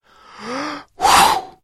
Звук от дуновения на одуванчик